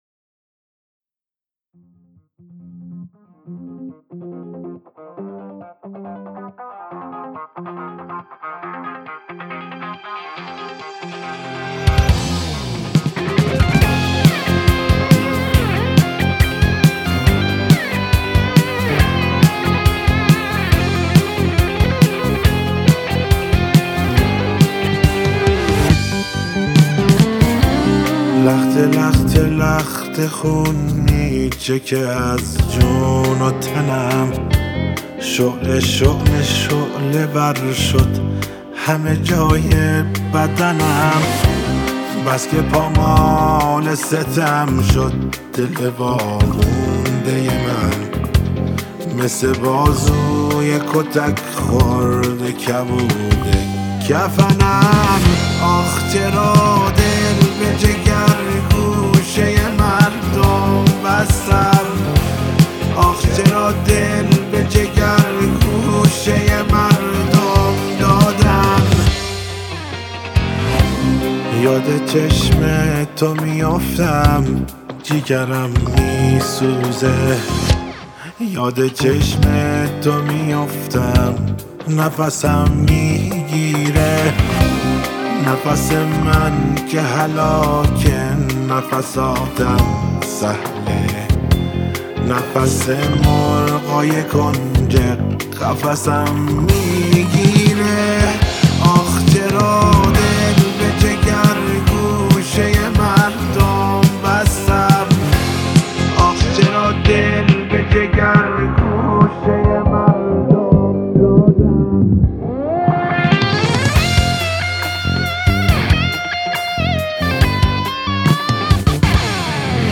فوق العاده احساسی و سوزناک